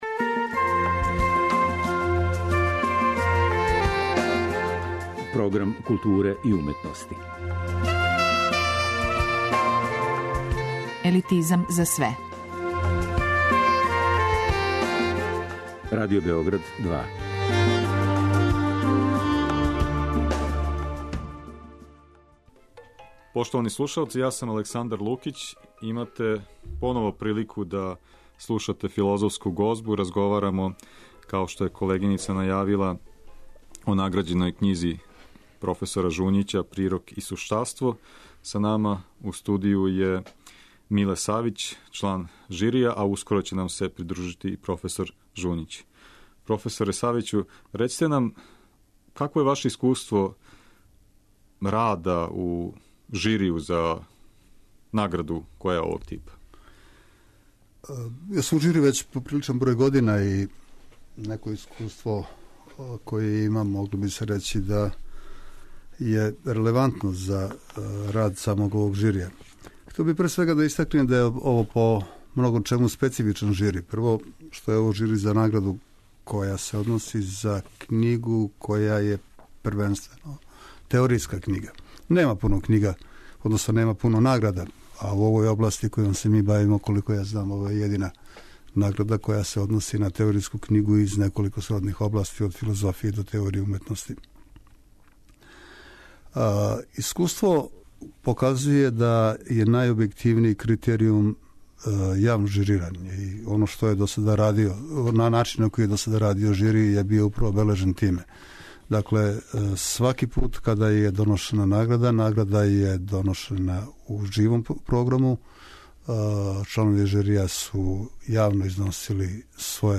Тема разговора биће управо разумевање стварности у погледу односа логике и онтологије, али и место и улога филозофије у данашњем свету.